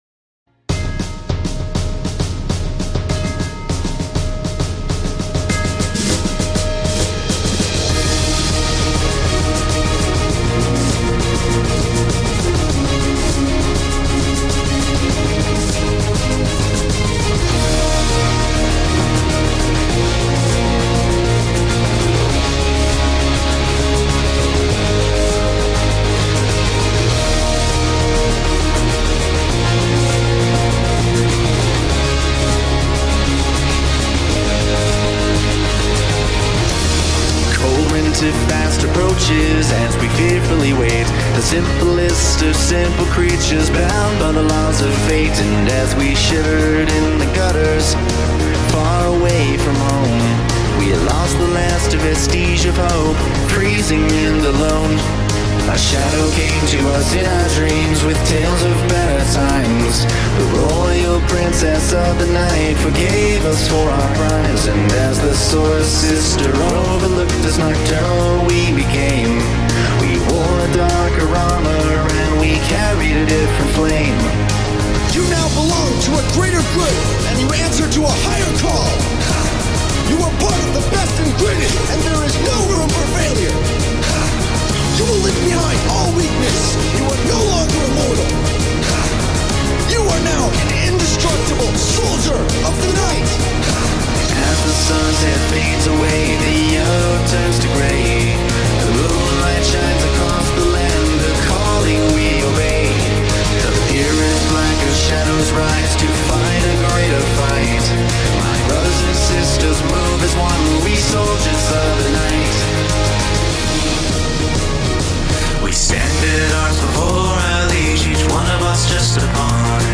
guitar and vocals
bass